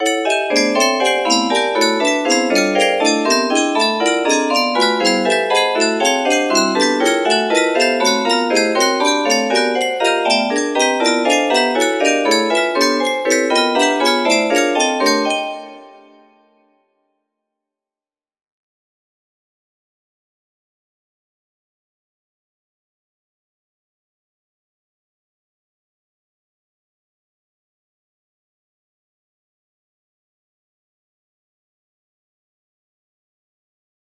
P17 music box melody